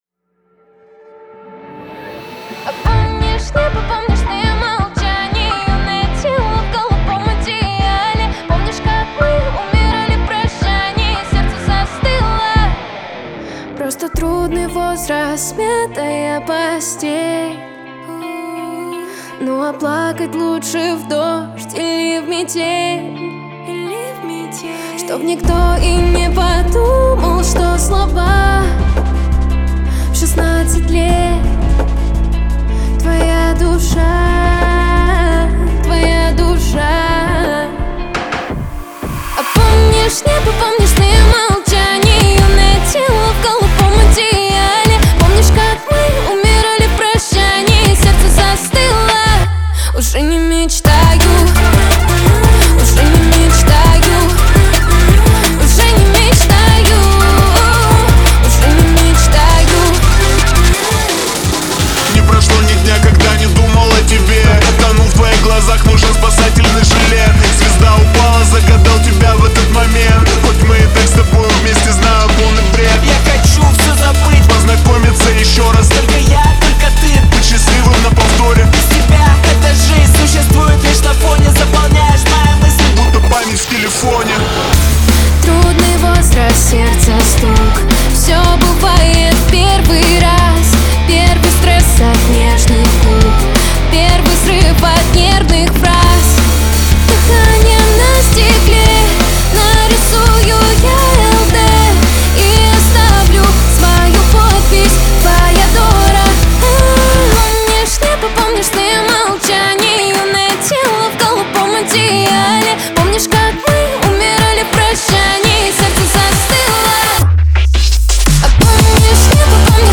Главная ➣ Жанры ➣ Hip-Hop/Rap. 2026.